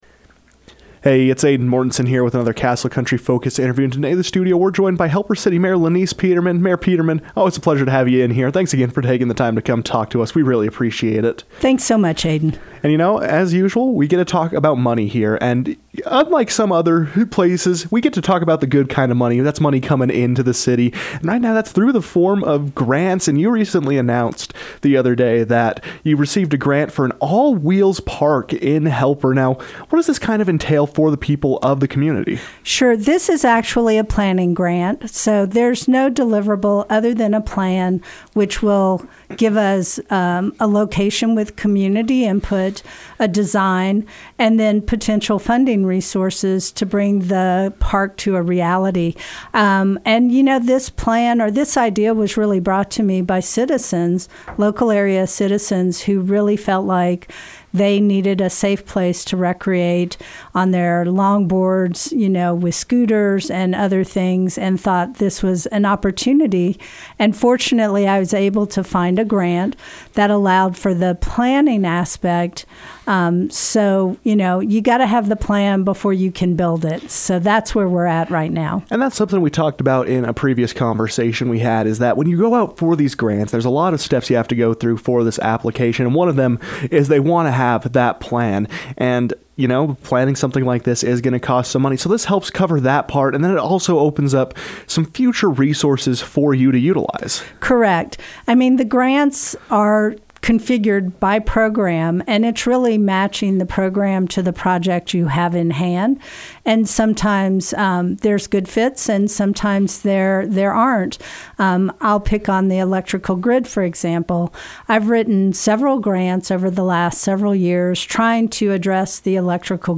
As the fiscal year comes to a close on June 30, cities scramble to finalize revisions to their current budget and approve a new budget to keep the city funded into the following year. Helper City Mayor Lenise Peterman joined the KOAL newsroom to explain what happens behind the scenes of the budget process, as well as to discuss some grants coming to the city.